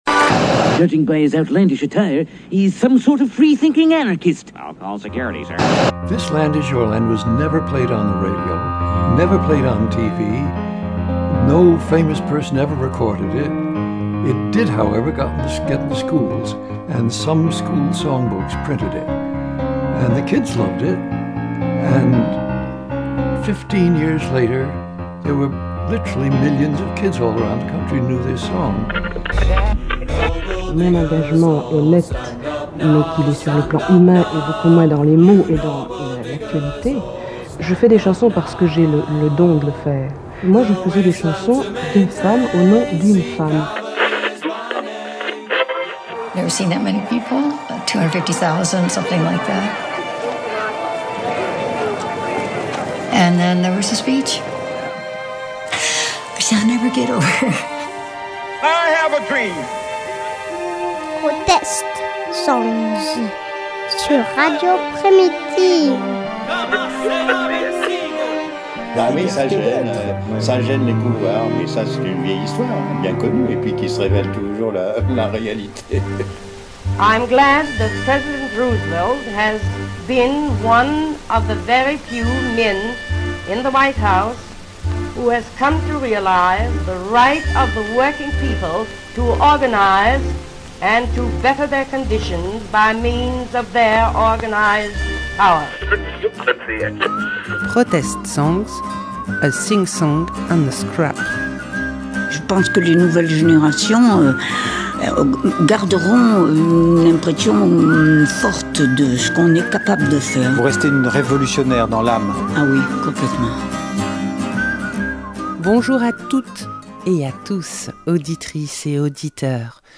🎧 Émission 2 - Protest songs